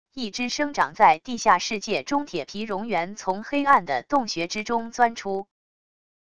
一只生长在地下世界中铁皮蝾螈从黑暗的洞穴之中钻出wav音频